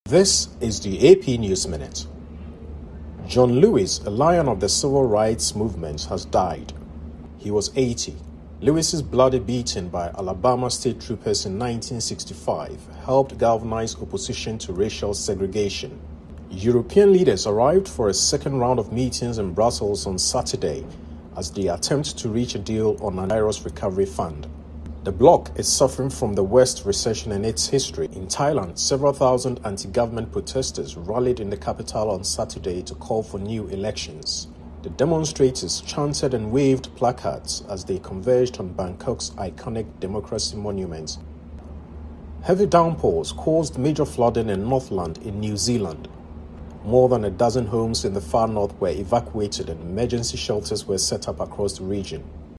News
美语听力练习素材:欧盟就"复苏基金"协议举行第二轮会议